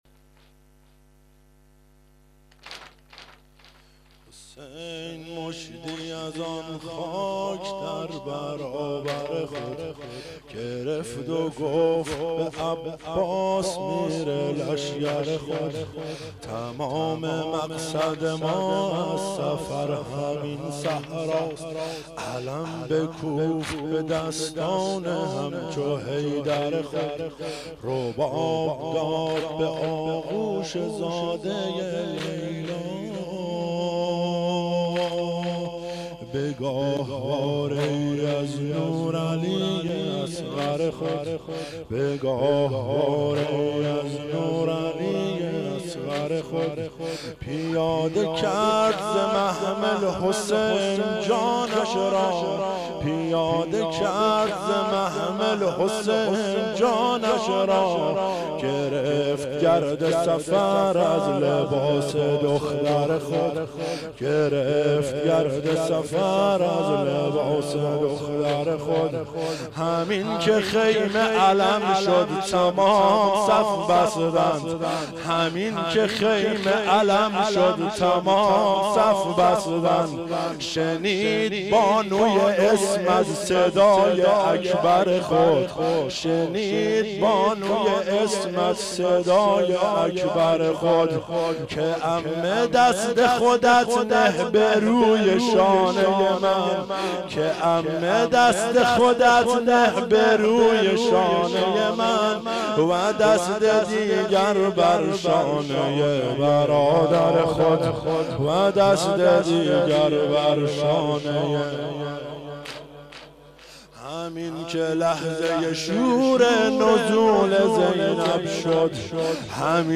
واحد شب دوم محرم 1391